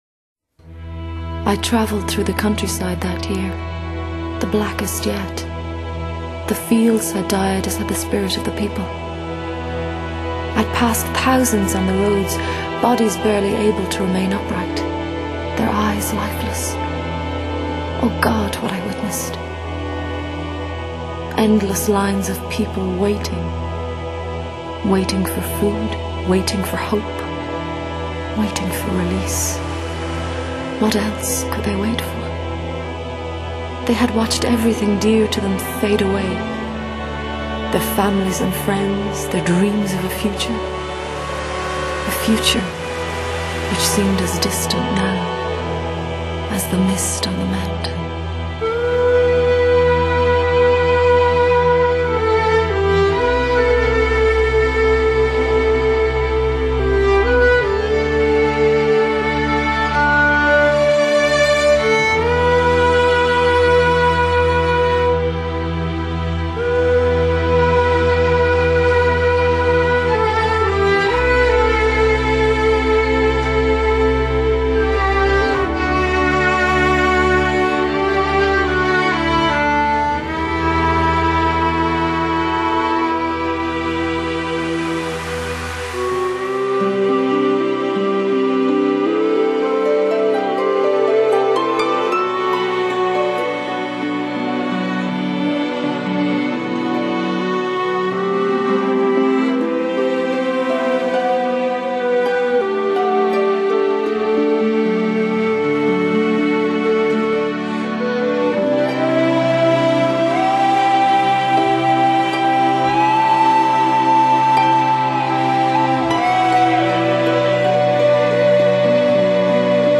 优美曼妙的歌声和娓娓动人的音乐，RUA亮眼的表现让她们邀约不断。